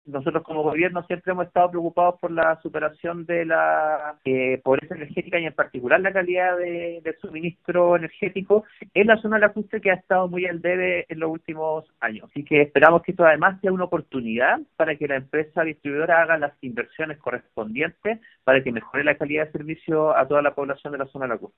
Por su parte, el seremi de Energía, Camilo Villagrán, espera que este fallo pueda convertirse en una oportunidad para que la distribuidora pueda generar inversiones para la zona lacustre.